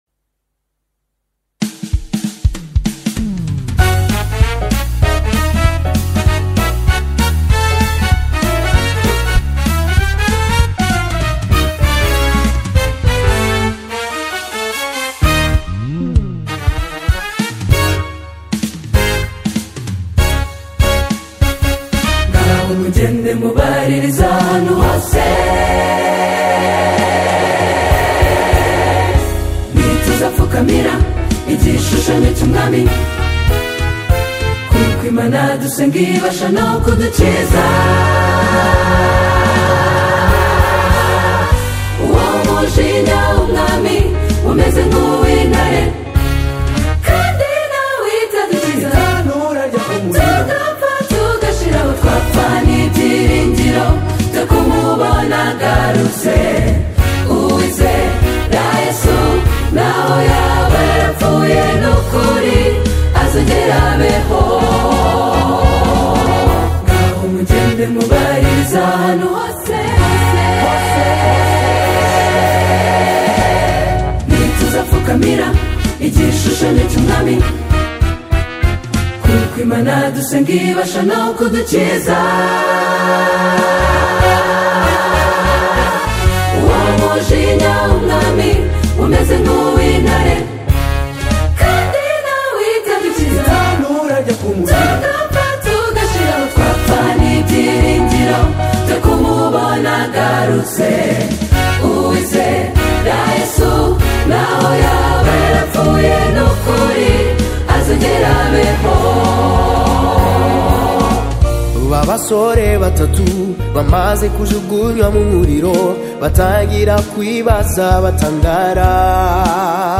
choral
anthem
signature vocal power and militant harmonic precision
vocal ensemble